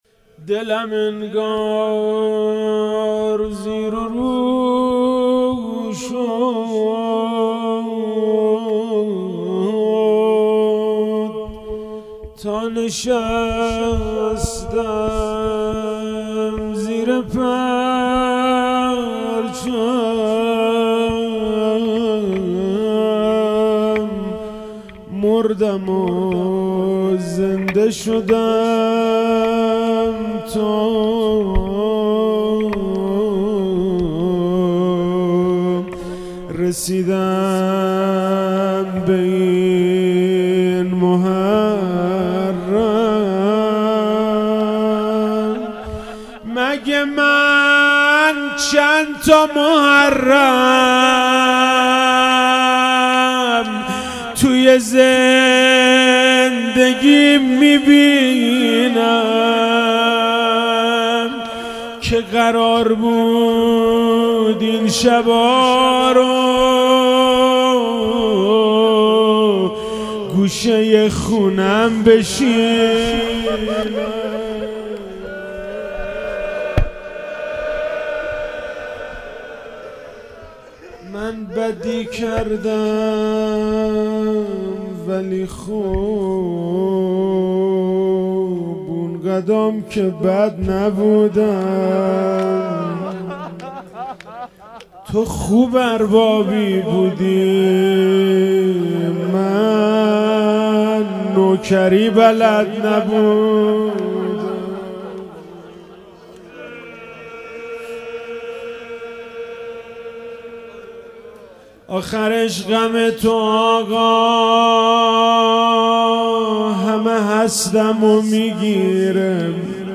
روضه - دلم انگار زیر و رو شد